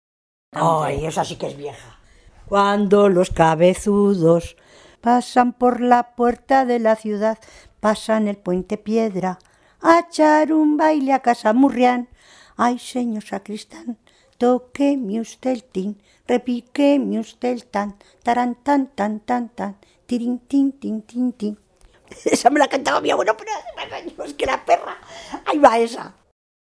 Clasificación: Cancionero